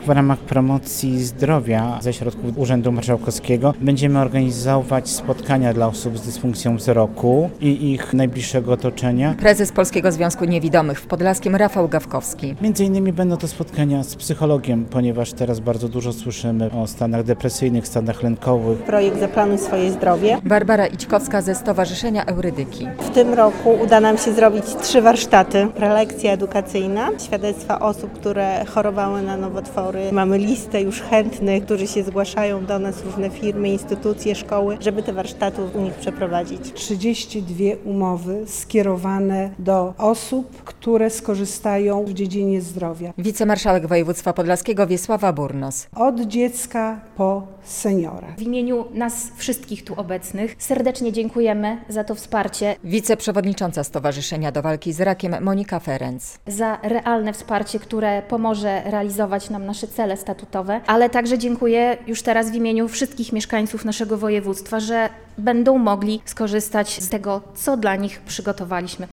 W Urzędzie Marszałkowskim w Białymstoku w piątek (6.06) przedstawiciele 30 podlaskich organizacji odebrali umowy na dofinansowania na przedsięwzięcia związane z promocją zdrowia.